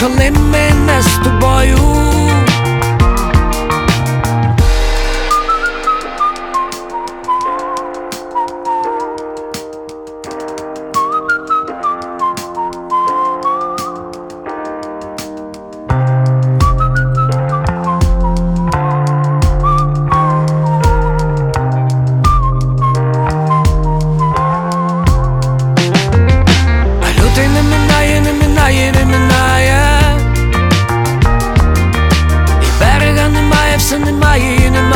Indie Pop Alternative
Жанр: Поп музыка / Альтернатива / Украинские